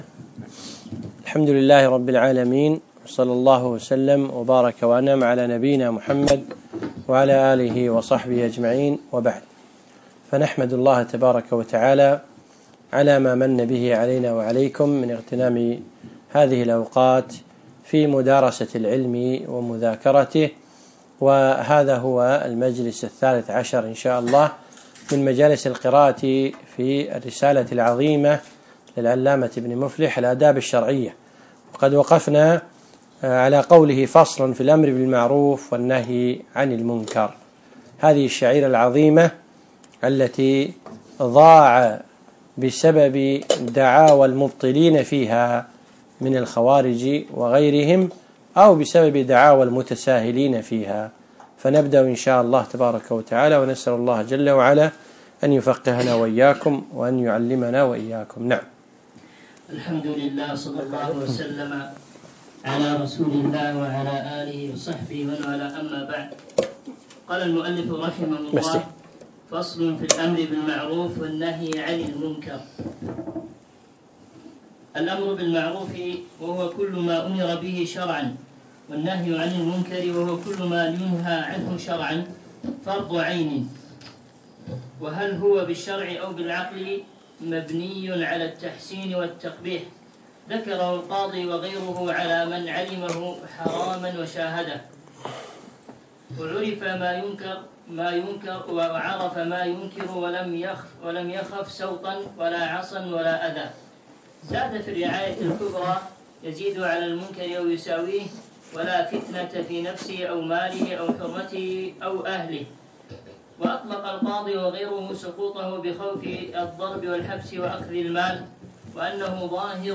الدرس الثالث عشر